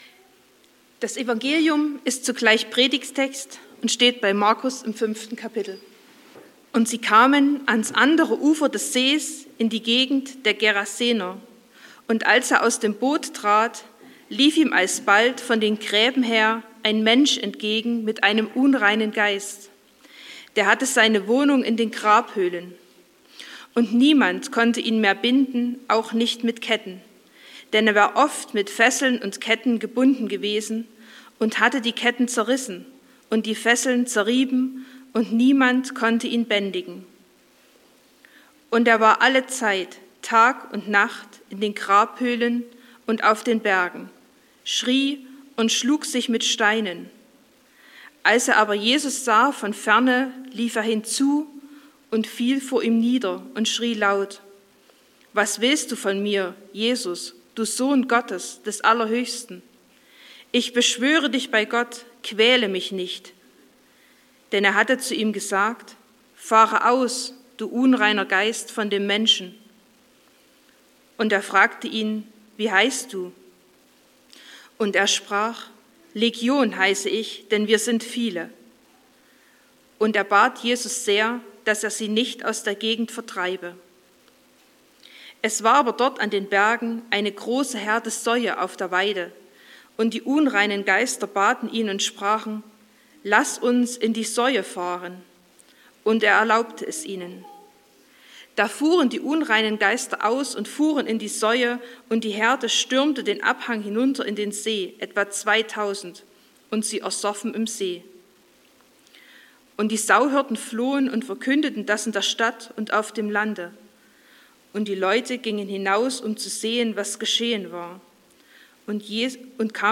11.01.2026 – Gottesdienst zur Allianz-Gebetswoche
Predigt und Aufzeichnungen